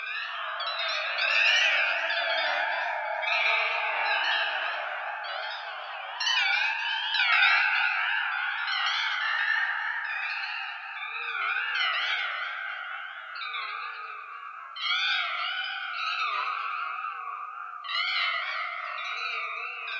strange_ghostly_ambience_loop.wav